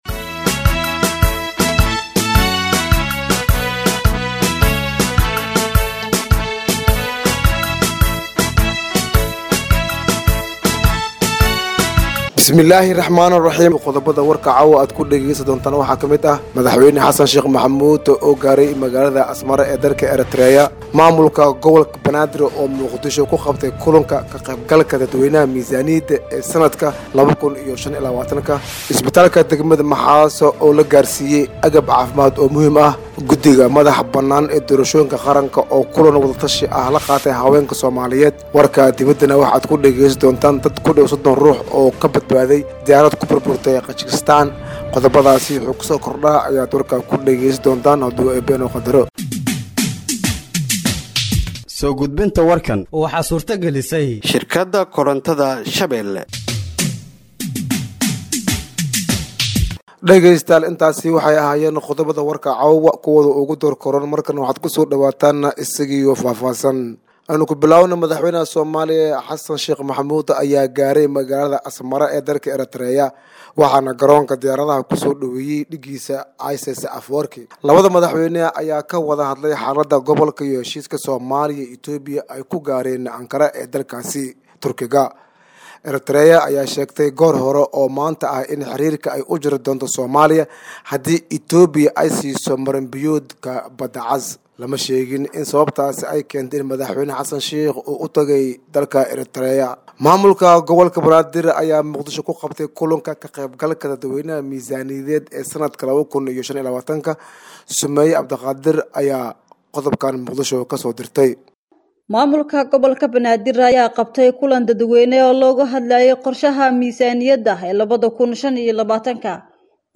Dhageeyso Warka Habeenimo ee Radiojowhar 25/12/2024